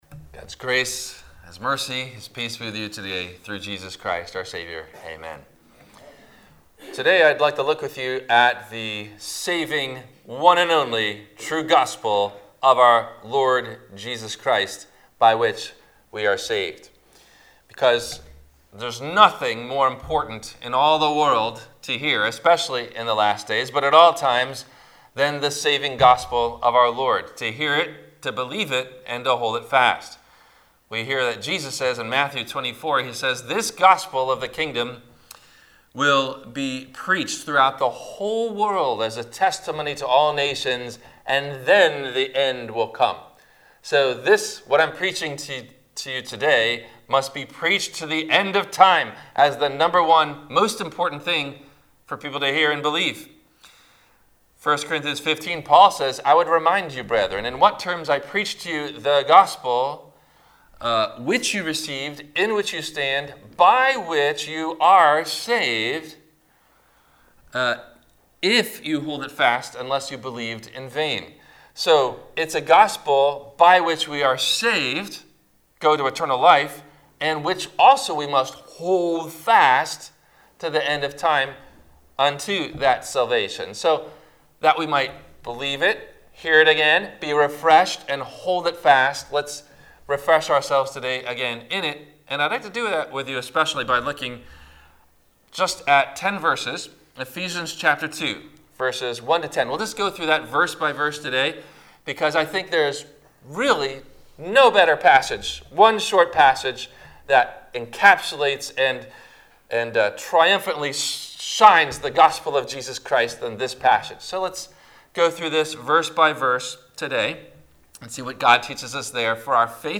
The True and Saving Gospel - Ephesians 2 - Sermon - January 31 2021 - Christ Lutheran Cape Canaveral